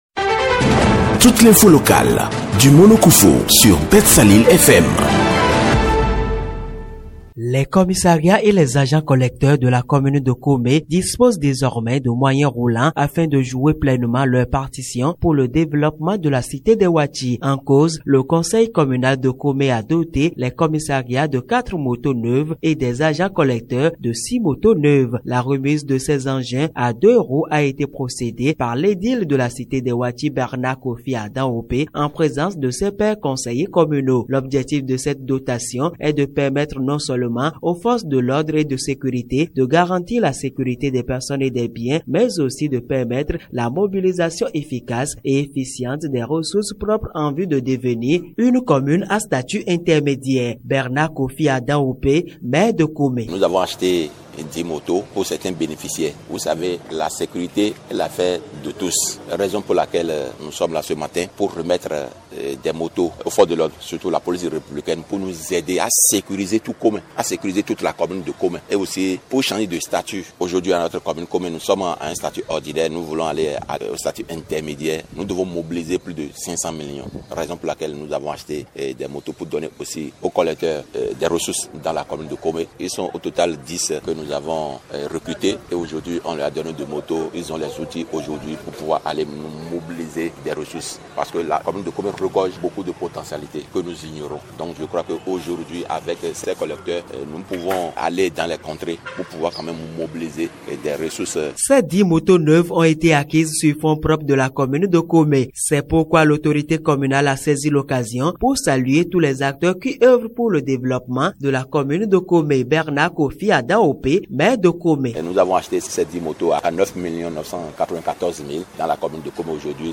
La commune de Comé a doté les commissariats de sa juridiction administrative et les agents collecteurs de dix (10) motos neuves. La cérémonie officielle de remise des clés de ces véhicules à deux roues a eu lieu ce mardi 28 janvier 2025 dans la cour de la mairie de Comé sous le regard très attentif du maire Bernard Adanhopé.
Voici le reportage